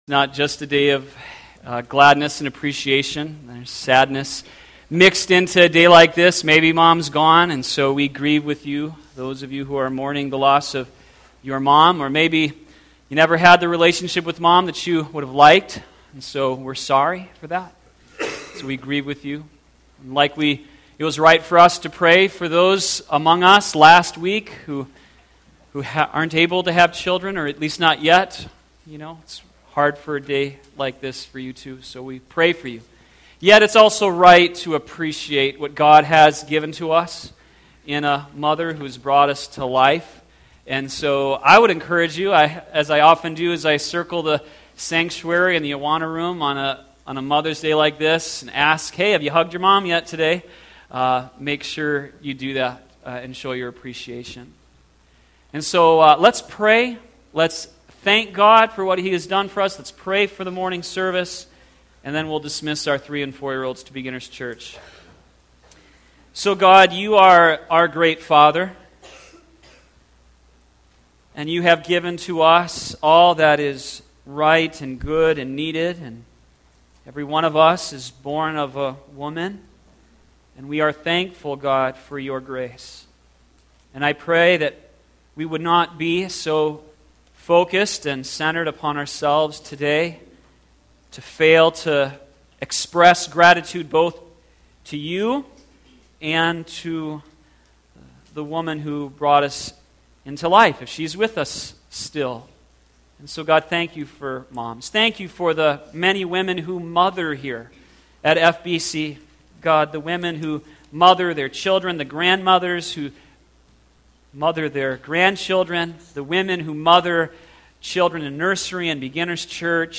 sermon51114.mp3